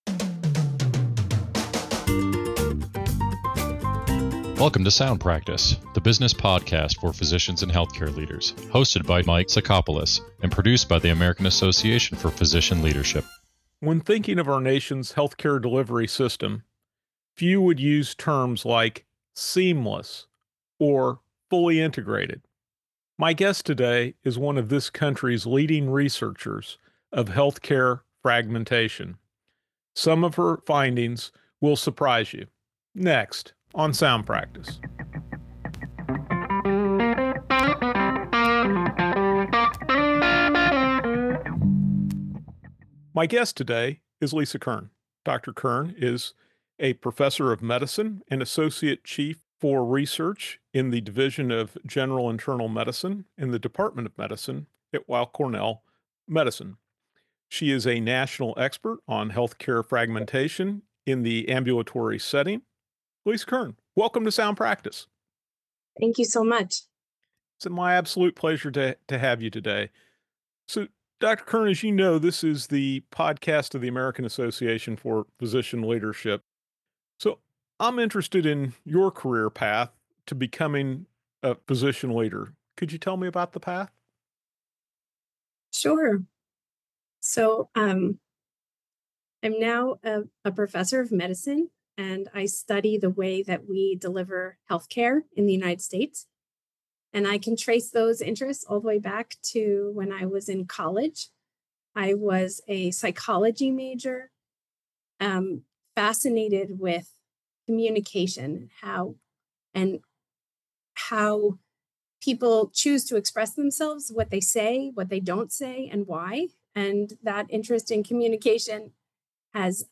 Tackling Healthcare Fragmentation: A Conversation